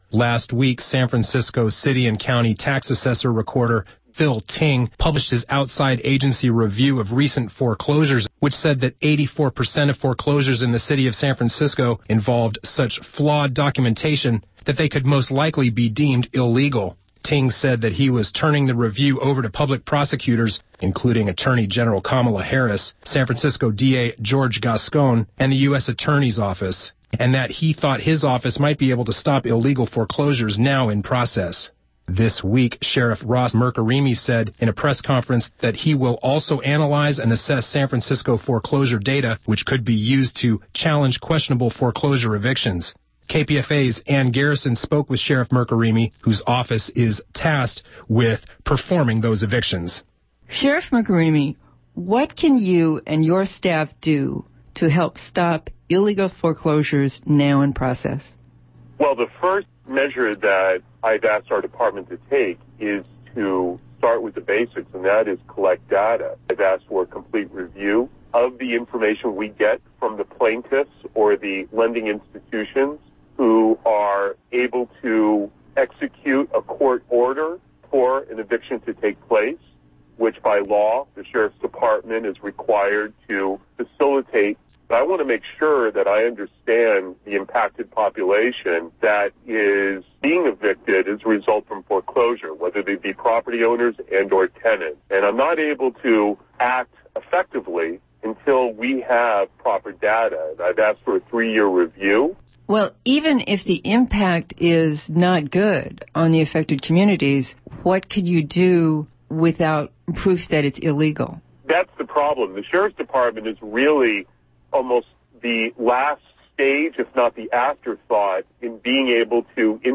Occupy has encouraged local legislators and public officials to challenge the legality of foreclosure evictions, and to propose alternatives to existing laws and financial institutions. On 02.25.2012, KPFA spoke to San Francisco Sheriff Ross Mirkarimi about how he is pushing the limits of his authority, as are San Francisco City Assessor Phil Ting and Supervisor John Avalos.
san_francisco_sheriff_ross_mirkarimi_on_foreclosures.mp3